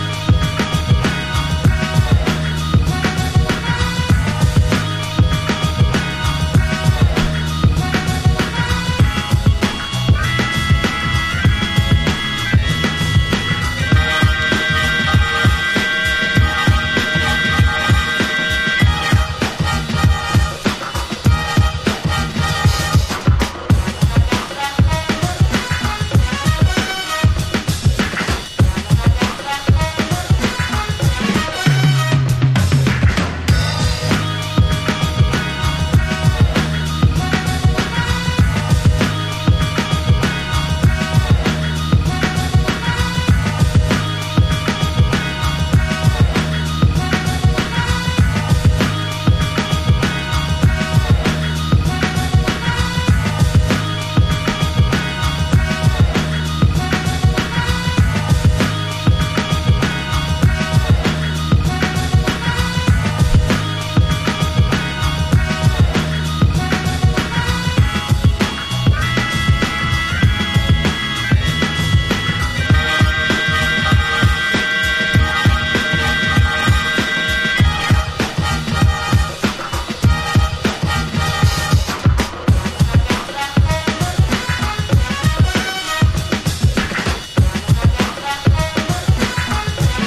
• HIPHOP
HIP HOPだけでなくソウル〜ジャズファンク好きにも刺さるドープグルーヴ由来の渾身の作品集。